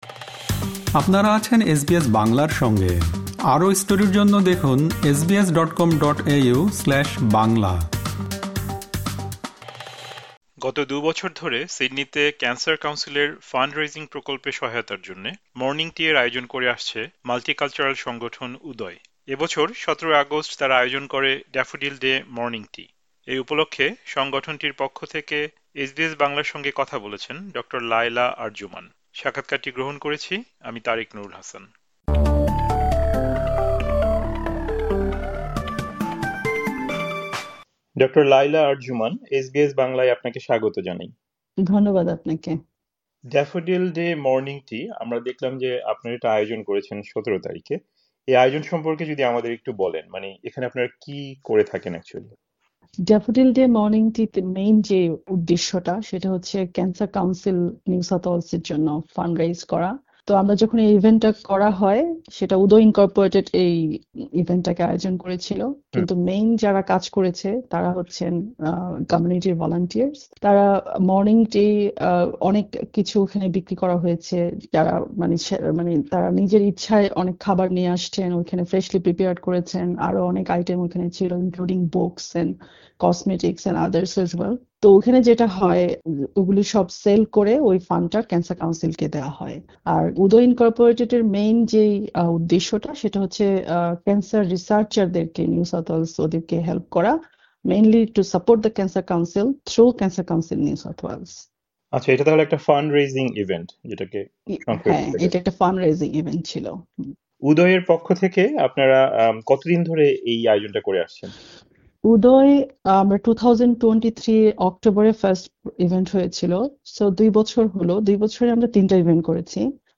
সম্পূর্ণ অডিও সাক্ষাৎকারটি